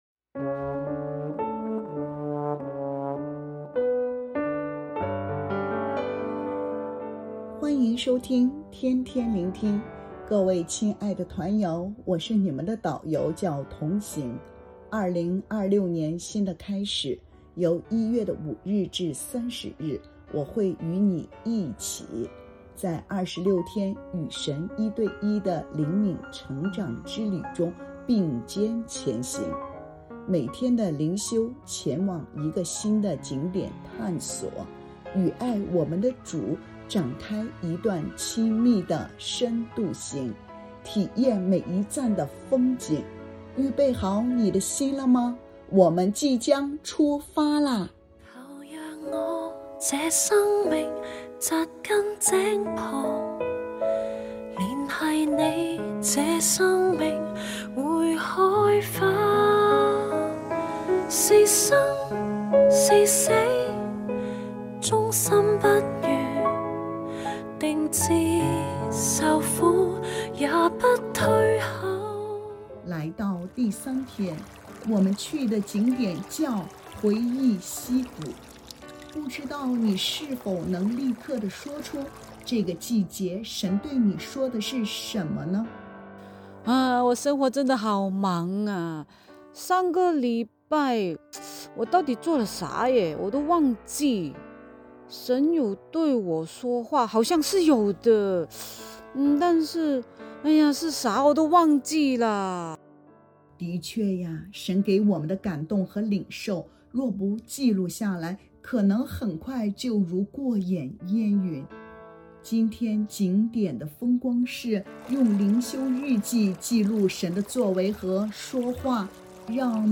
🎶靈修詩歌：《垂絲柳樹下》玻璃海樂團